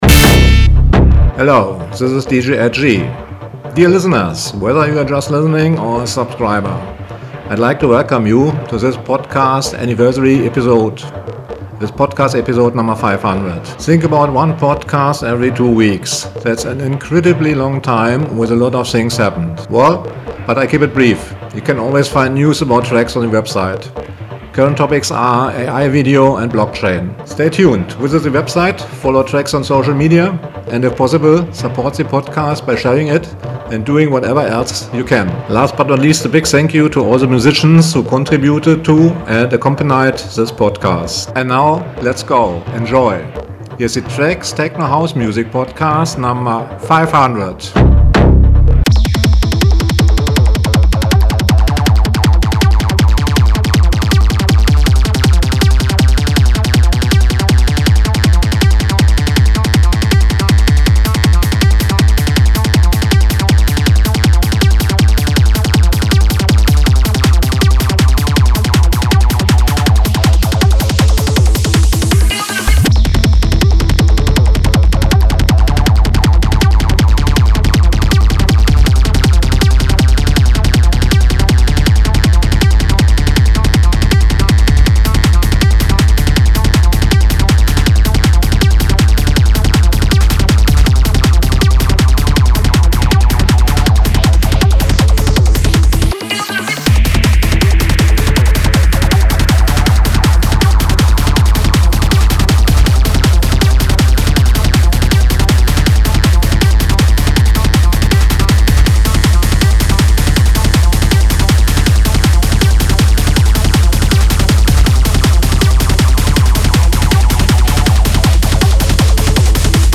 Club Musik, schnell, sphärig und housig